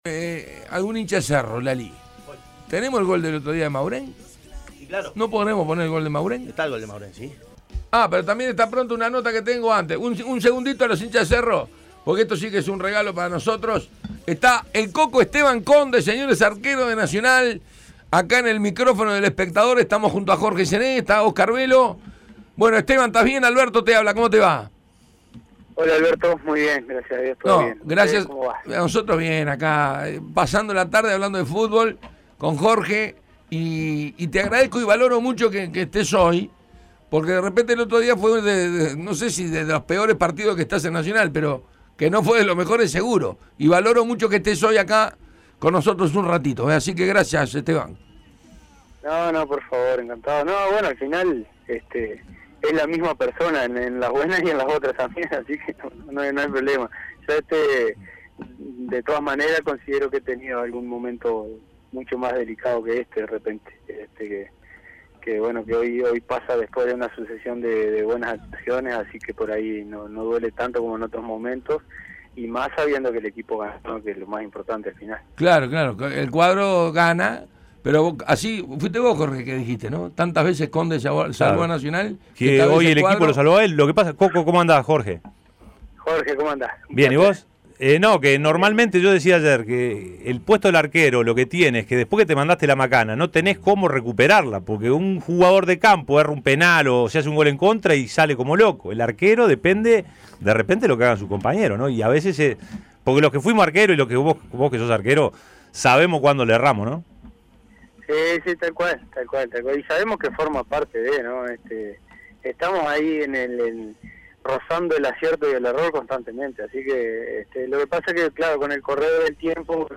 El golero de Nacional, Esteban "Coco" Conde, habló con el panel de Tuya y Mía. Además de analizar su presente personal y el del equipo, se expresó acerca de su niñez y de la admiración que tenía por Jorge Seré. También repasó las mejores atajadas que hizo desde que está en Nacional